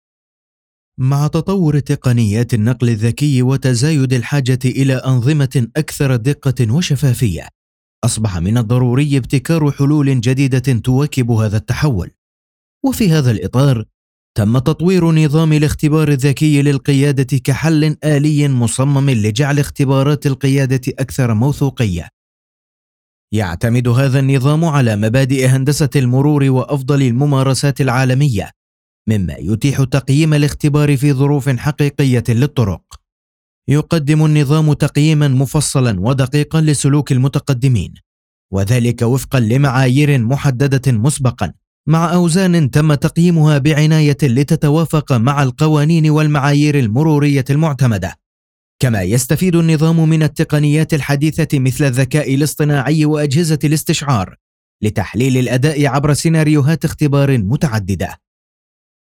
Male
A warm, confident Arabic voice with a clear, polished tone that instantly builds trust. The delivery is natural, steady, and engaging, balancing professionalism with approachability.
Normal Narration